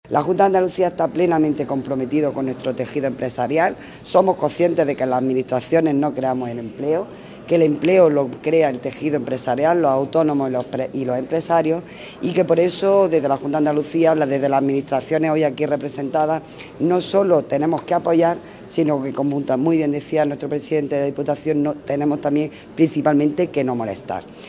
ARANZAZU-MARTIN-DELEGADA-GOBIERNO-JUNTA.mp3